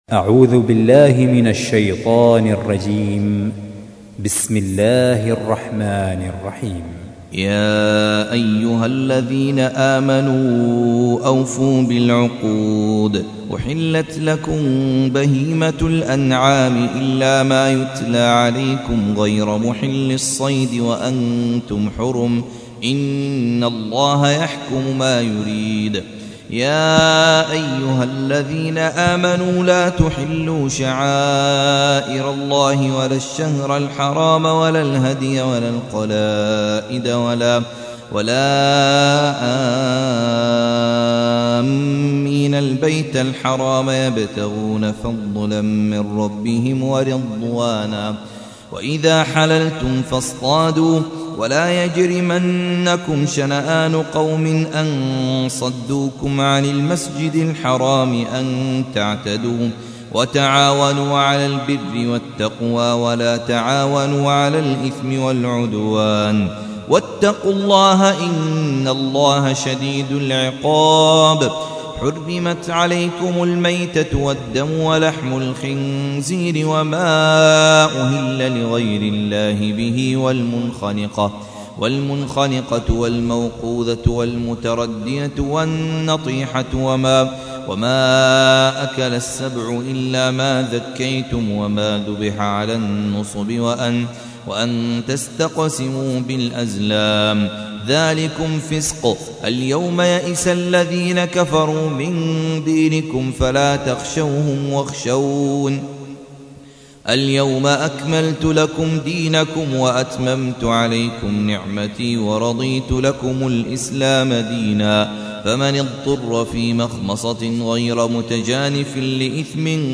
تحميل : 5. سورة المائدة / القارئ خالد عبد الكافي / القرآن الكريم / موقع يا حسين